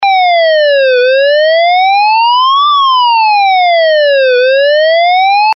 Serie: SIRENAS DE GRAN POTENCIA ACÚSTICA - SIRENAS DIRECCIONALES
45 Sonidos seleccionables - 141dB